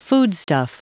Prononciation du mot foodstuff en anglais (fichier audio)
Prononciation du mot : foodstuff